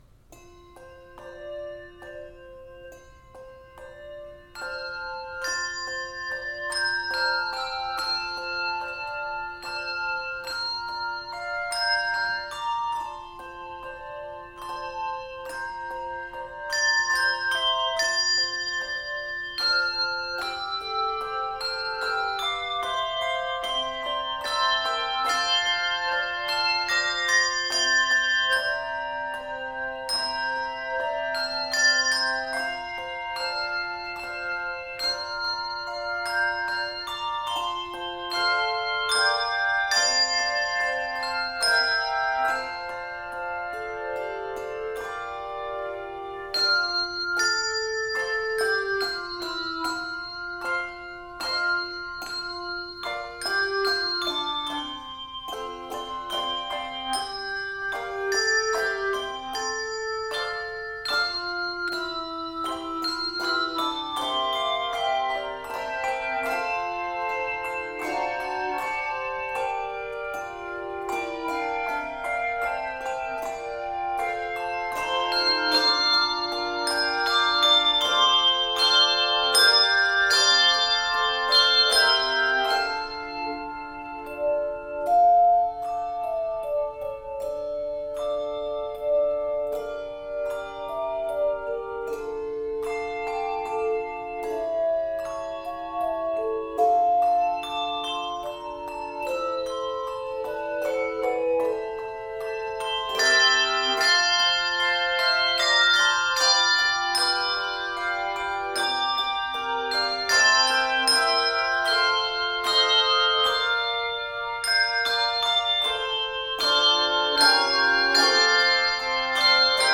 Key of F Major.
Octaves: 3